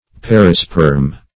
Perisperm \Per"i*sperm\, n. [F. p['e]risperme. See Peri-, and